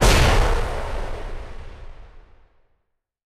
hit1.ogg